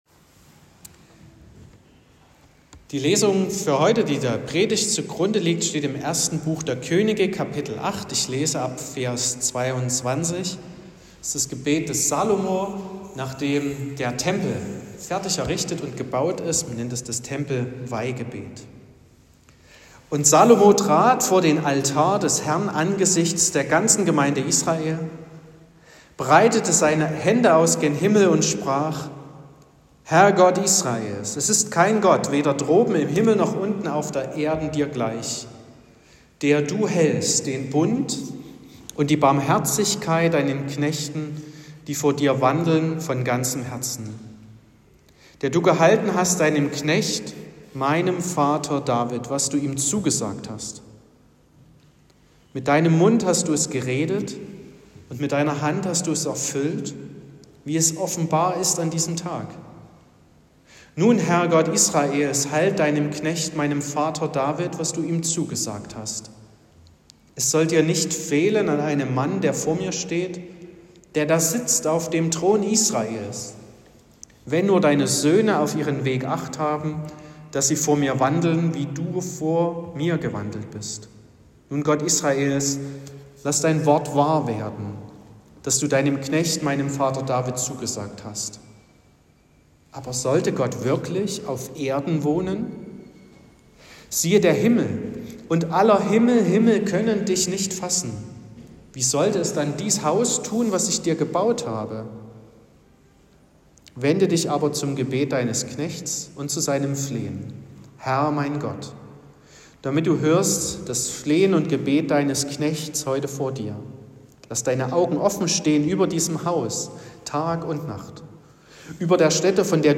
29.05.2025 – gemeinsamer Mehr-Licht-Gottesdienst
Predigt (Audio): 2025-05-29_Zwischen_Himmel_und_Erde.m4a (8,0 MB)